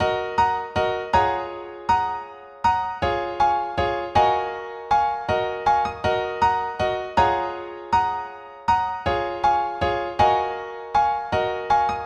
Proud Keys 159 bpm.wav